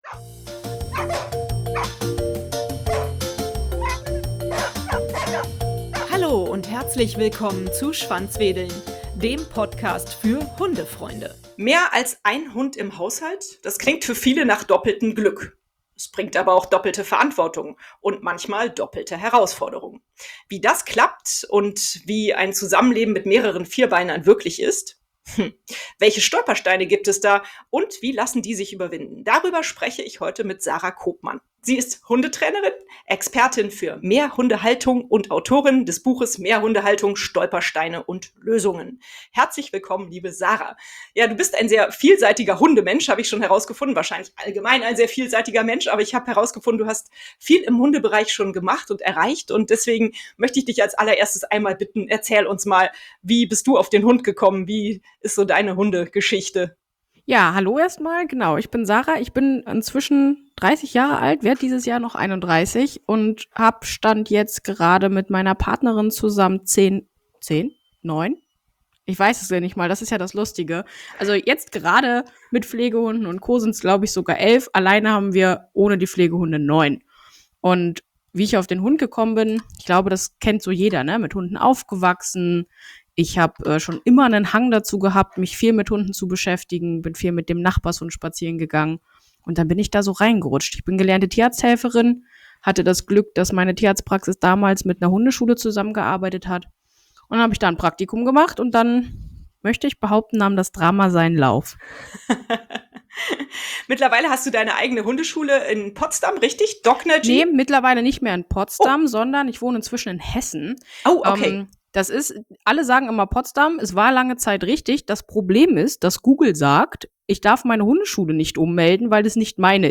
Herzlich willkommen im Interview